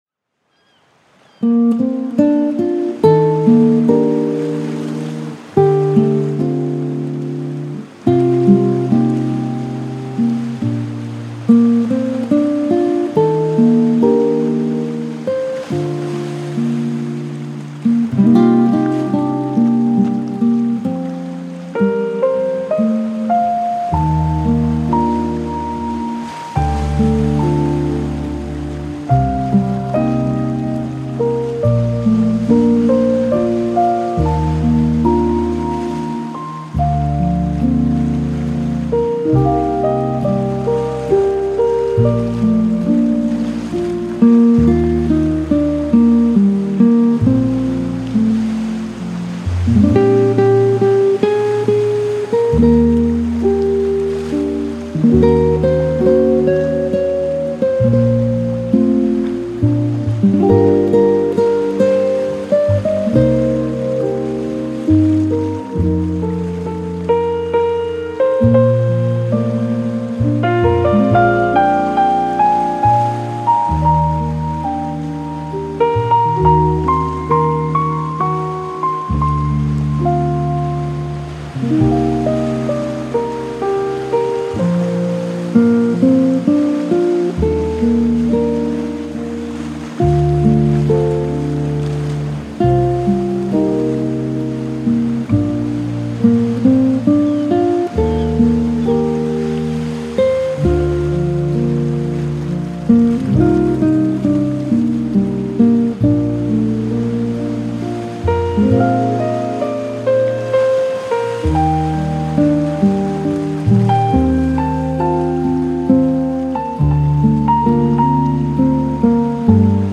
Колыбельные под звуки